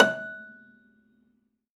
53p-pno16-F3.wav